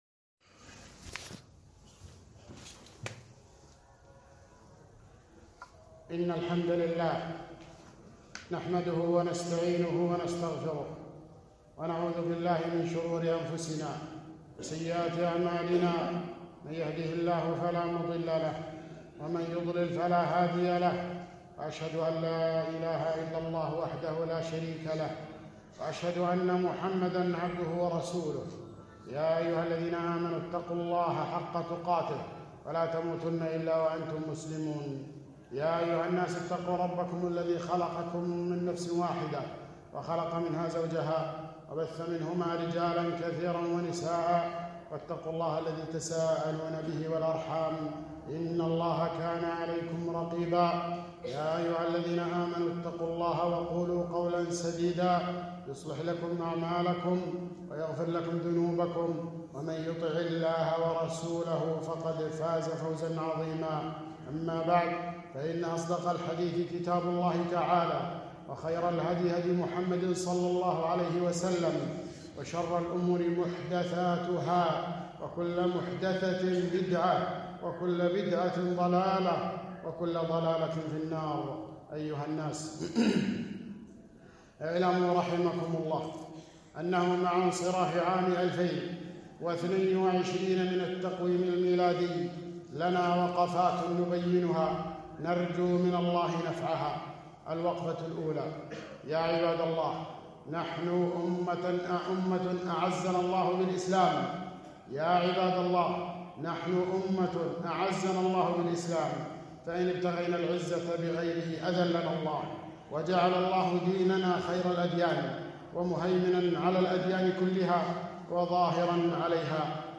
خطبة - رأس السنة